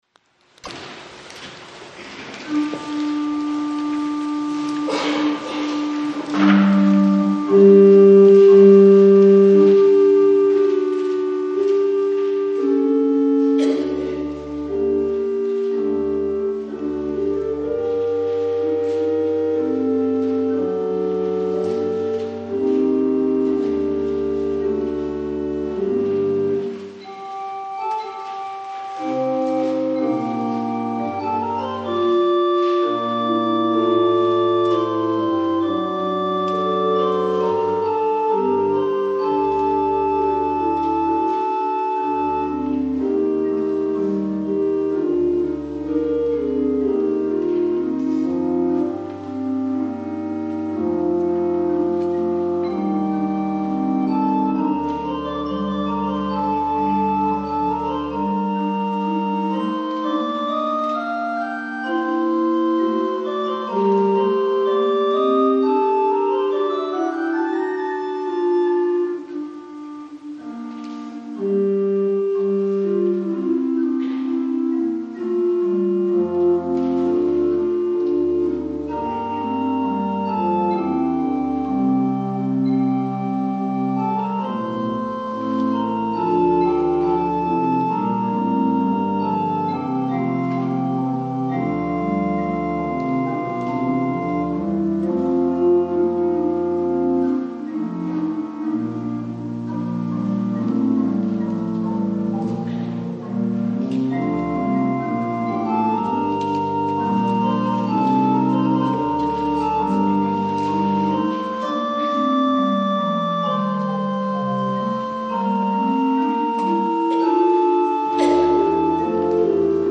2026年1月25日広島流川教会礼拝